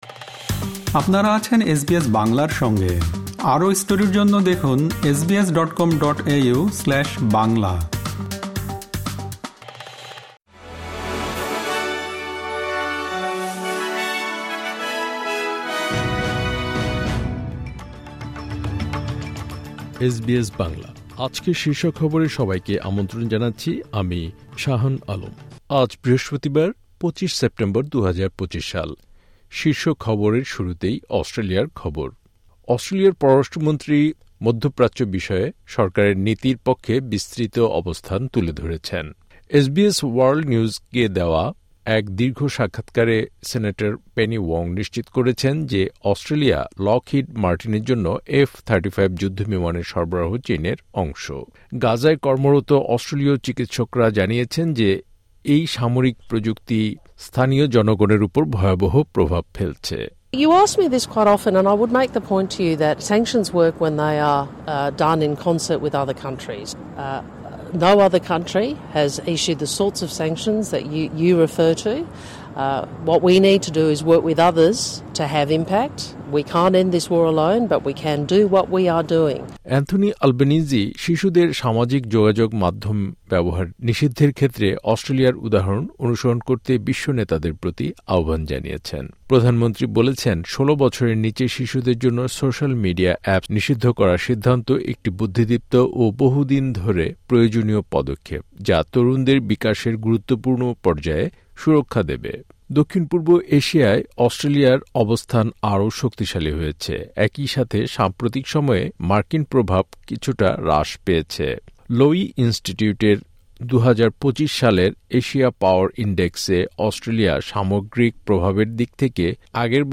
এসবিএস বাংলা শীর্ষ খবর: ২৫ সেপ্টেম্বর, ২০২৫